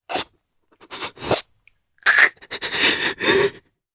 Riheet-Vox_Sad_jp.wav